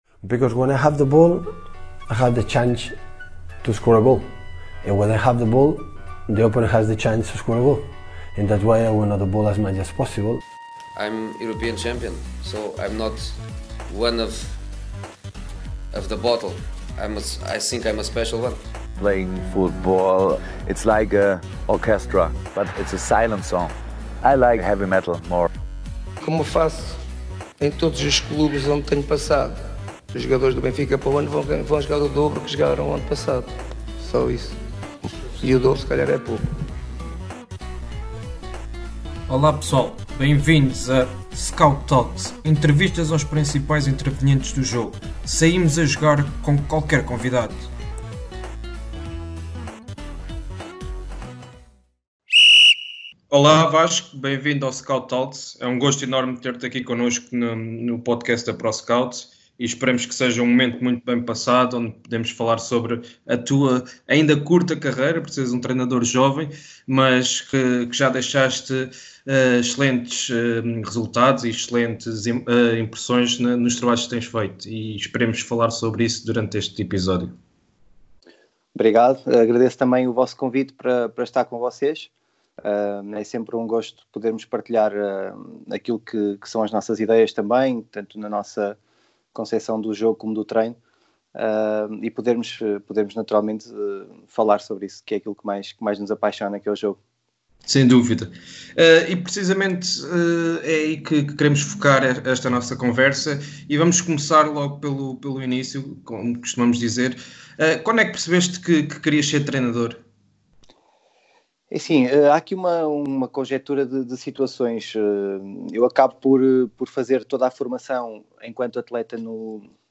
Scout Talks é um podcast da ProScout onde entrevistamos os principais intervenientes do jogo.